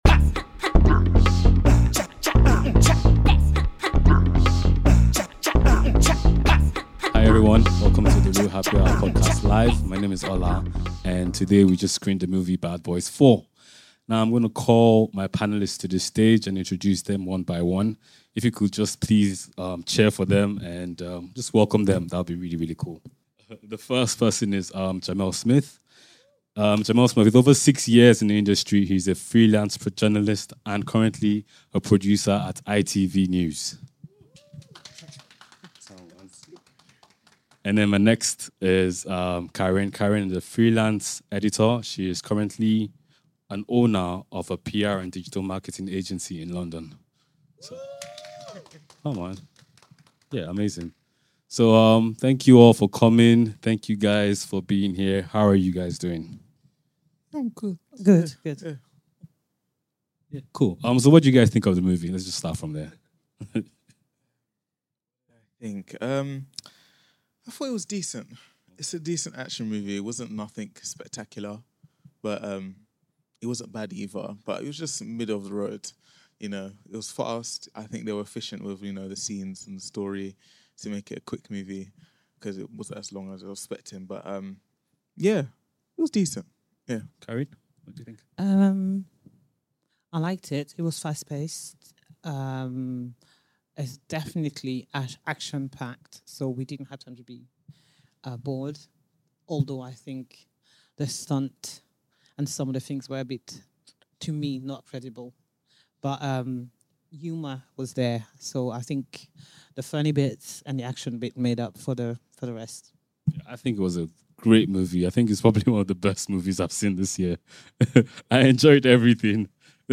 The Reel Happy Hour Podcast Live Presents: A Special Film Event at Genesis CinemaJoin us on July 23rd, 2024, for an exclusive screening of "FACES," a gripping multi-narrative feature film that explore...